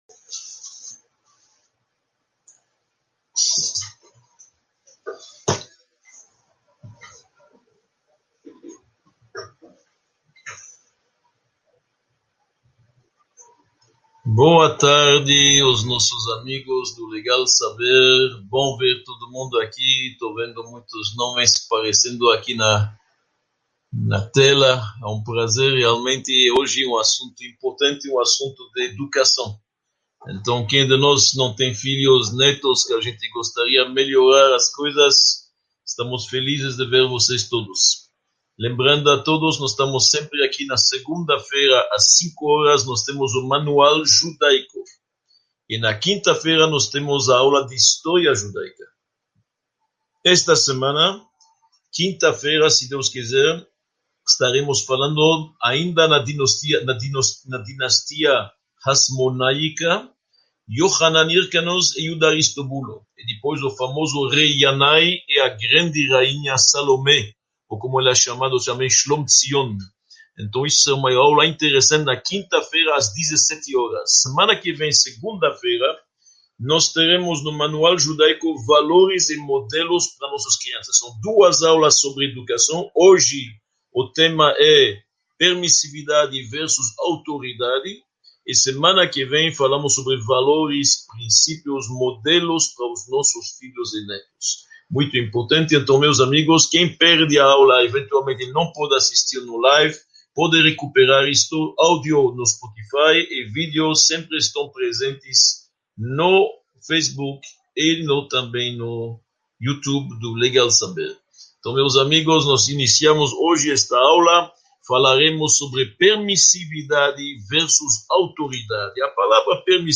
17 – Permissividade x Autoridade: o que é melhor para os filhos?| Módulo I – Aula 17 | Manual Judaico